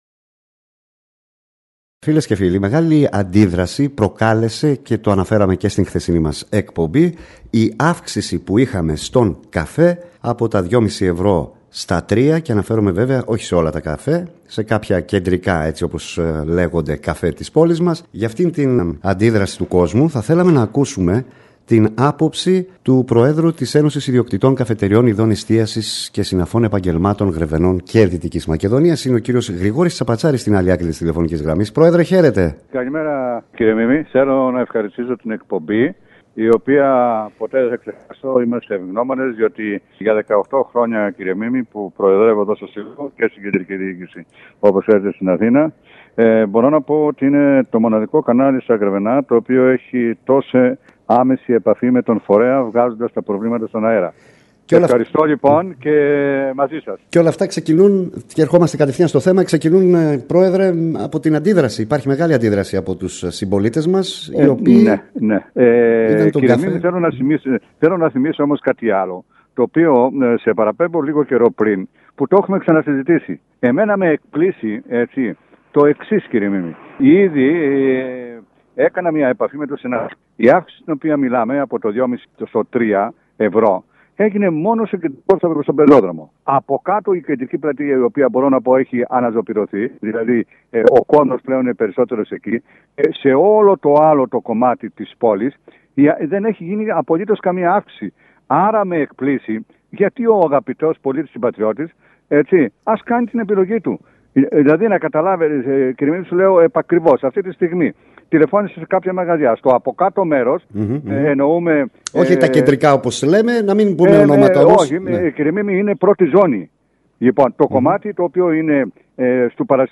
ΓΡΕΒΕΝΑ ΟΙΚΟΝΟΜΙΑ ΣΥΝΕΝΤΕΥΞΗ